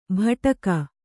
♪ bhaṭaka